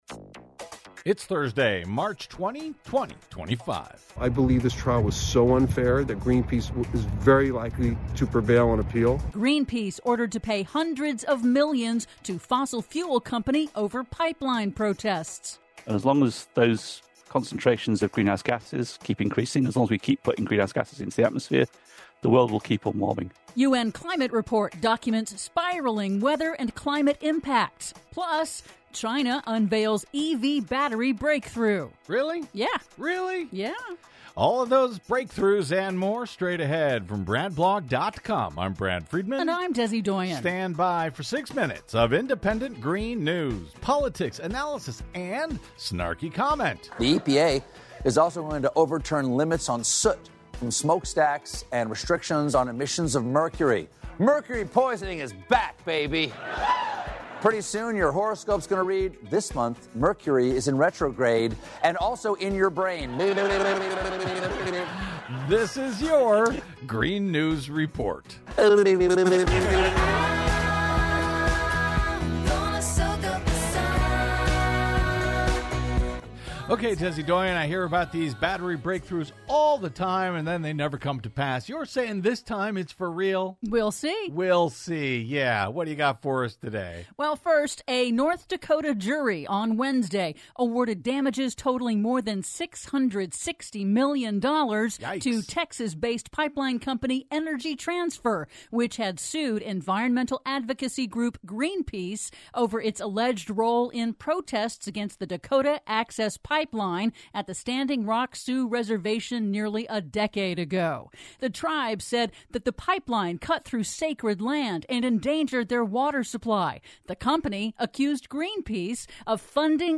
'GREEN NEWS EXTRA' (Stuff we didn't have time for in today's audio report)...